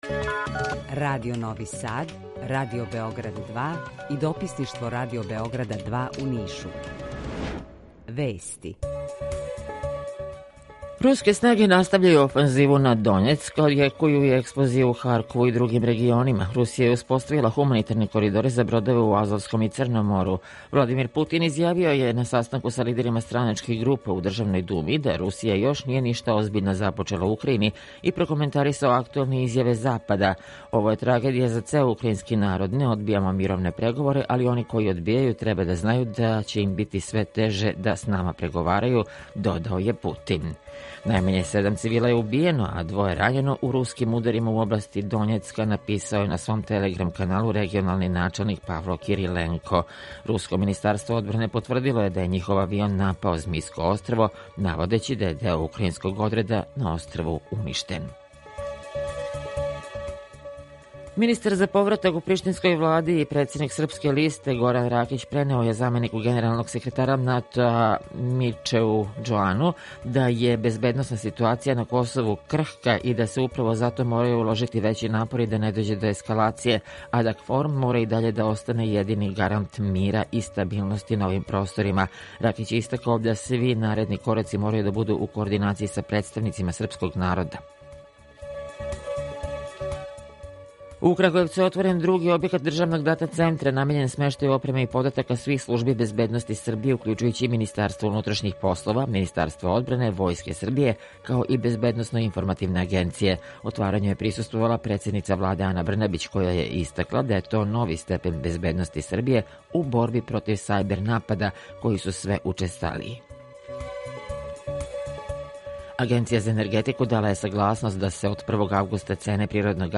Емисију реализујемо заједно са студиом Радија Републике Српске у Бањалуци и са Радио Новим Садом.
У два сата, ту је и добра музика, другачија у односу на остале радио-станице.